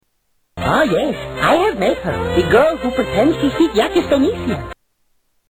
Pretends to speak Yaki
Category: Television   Right: Personal